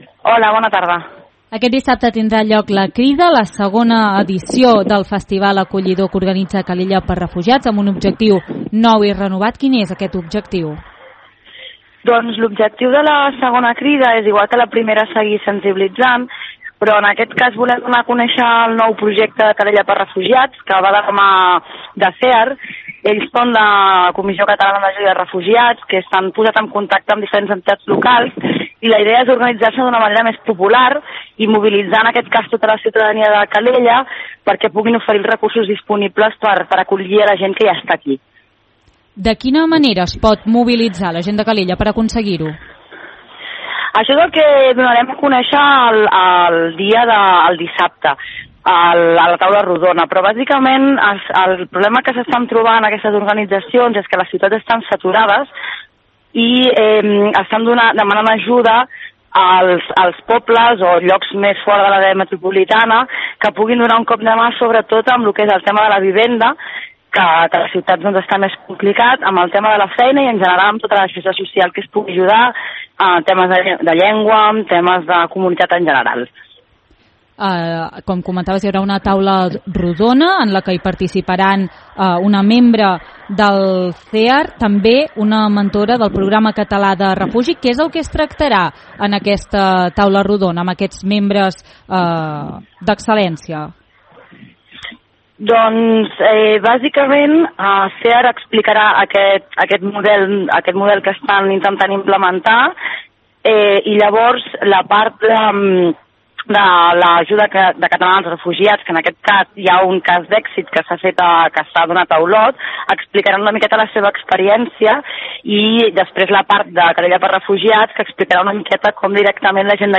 2698-ENTREVISTA-REFUGIATS-SENSE-INTRO.mp3